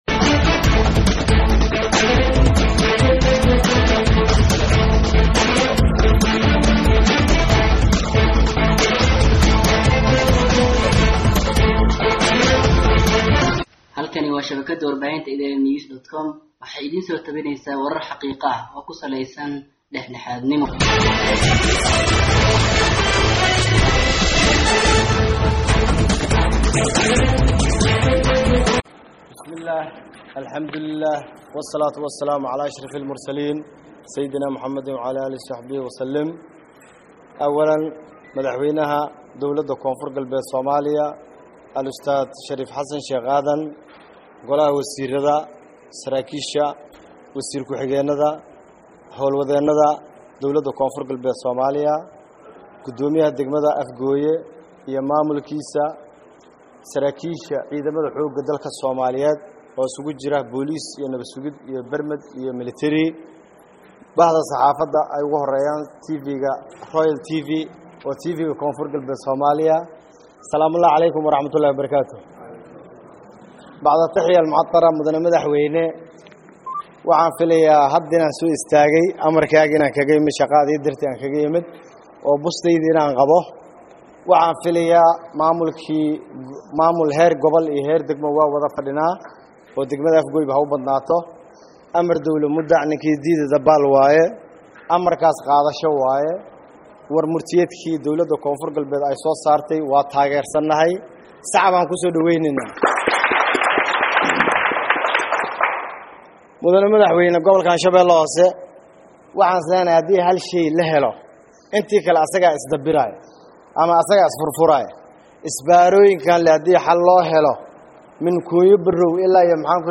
Afgooye(INO)-Gudoomiyaha Gobolka shabeelaha Hoose, C/qaadir Nuur Sidii  ayaa khudbad ka jeediyaya munaasabad ay isugu imaadeen madaxda Dowladda  Koonfur Galbeed soomaaliya oo ka dhacdey Degmada Afgooye Ee Gobolka Shabellaha Hoose.